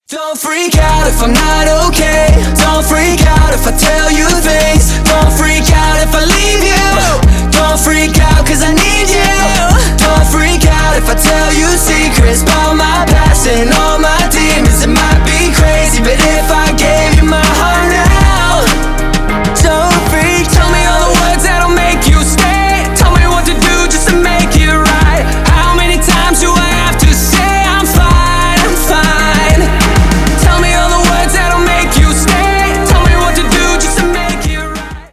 Рэп и Хип Хоп
громкие